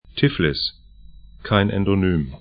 Pronunciation
Tiflis 'tɪflɪs T‘bilisi dbi'lɪsi geor.